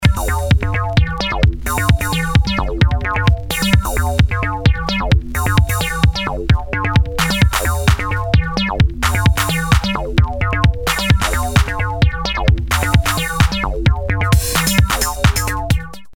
• Category Techno